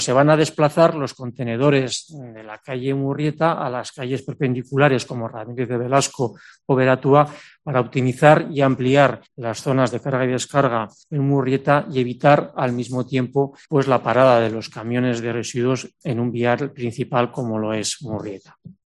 Jaime Caballero, concejal de Desarrollo Urbano Sostenible